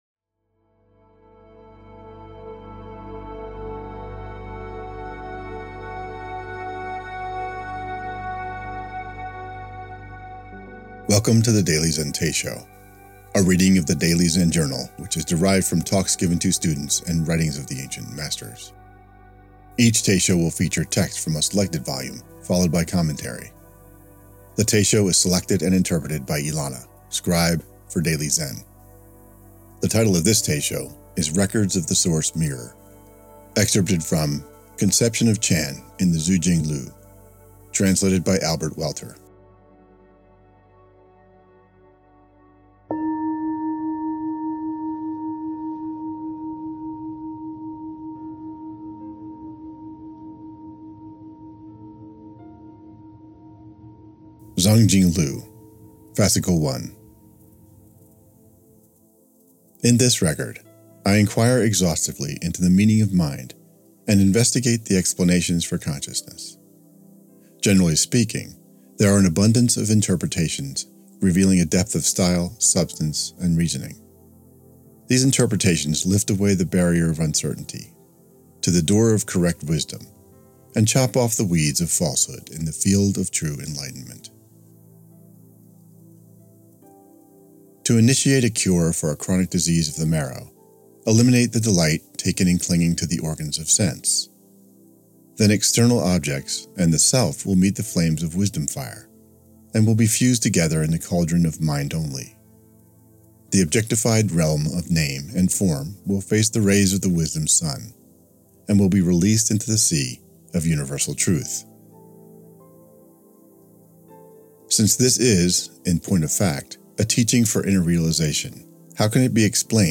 The Daily Zen Teisho